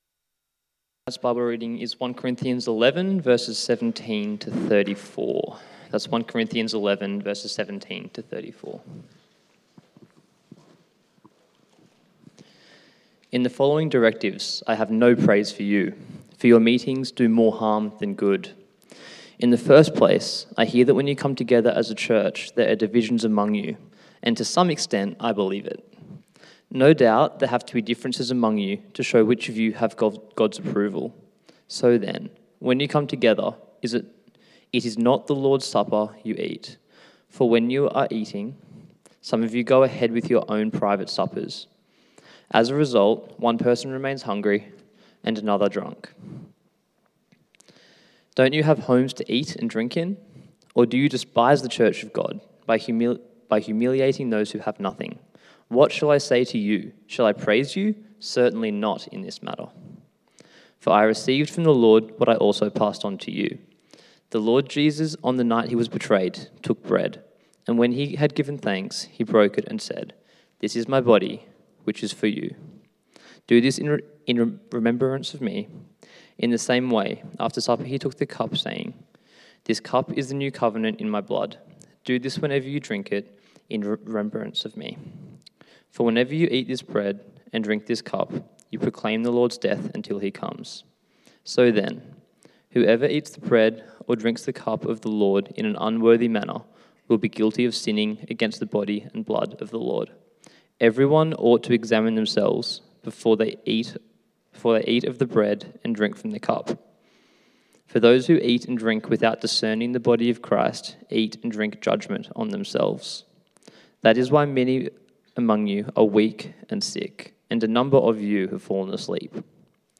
Service Type: 6PM